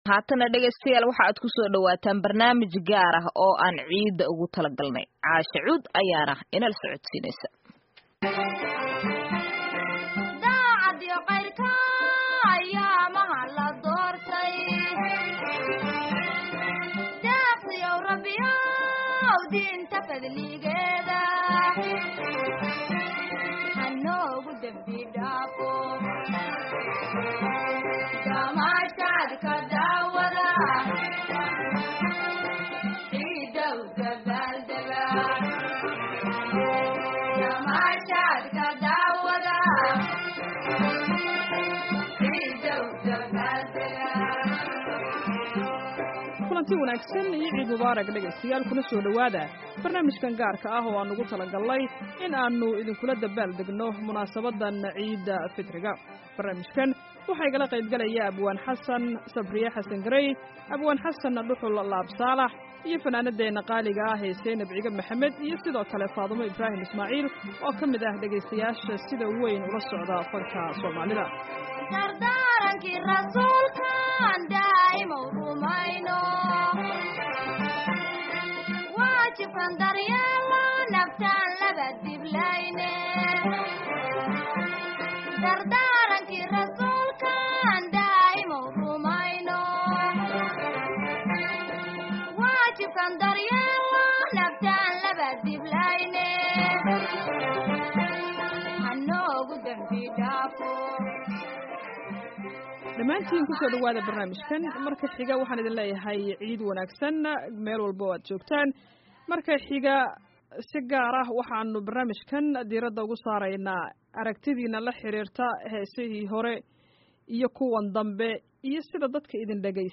Barnaamij faneedkan gaarka ah waa mid madadaalo ah oo aan ugu talogalnay in aan idinkula damaashaadno munaasabadda Ciiddul Fitriga, waxaana aad kaga bogan doontaan heeso kala duwan.